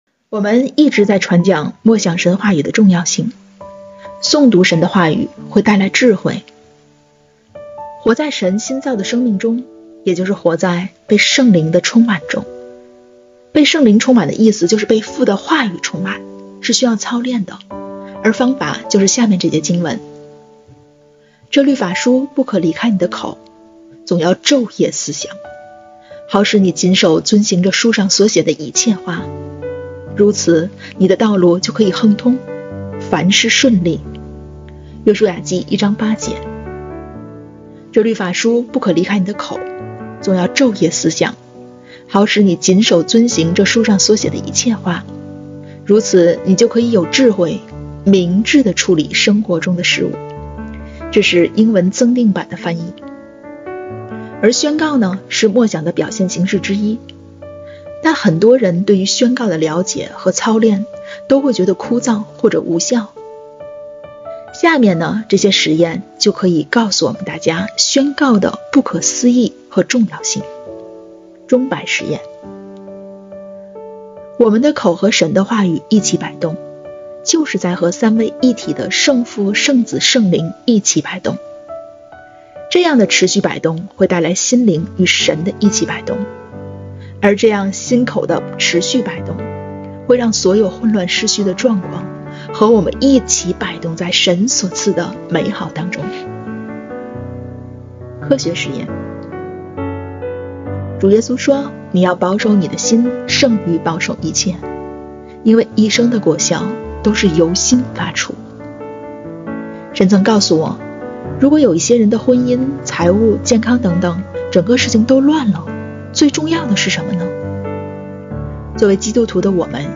竹简点读法（讲解）